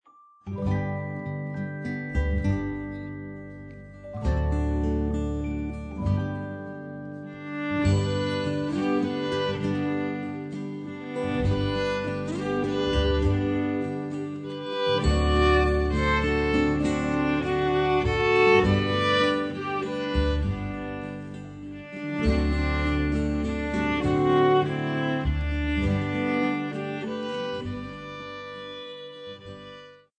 32 Weihnachtslieder aus verschiedenen Ländern für 1-2 Violen
Besetzung: 1-2 Violen mit CD